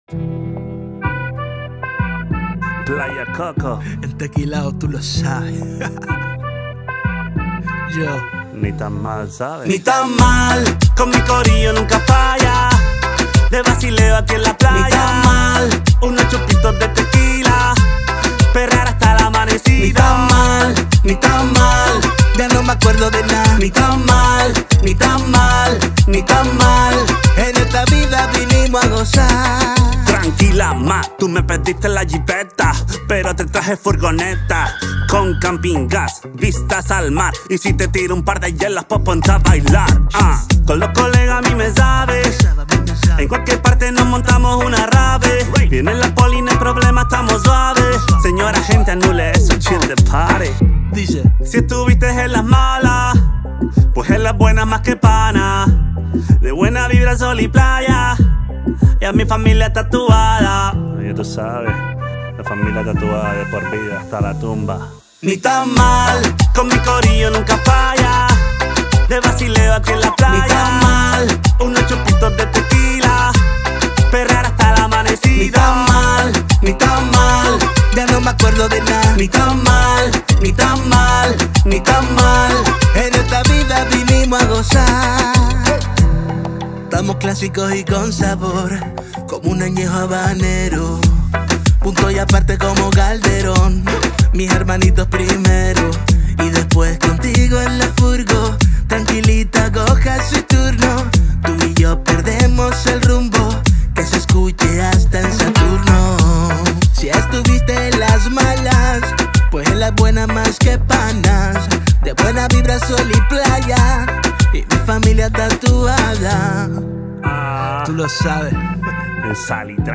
Guitarra Contactar Ver teléfono Leer más 🌴 ¡Aloha!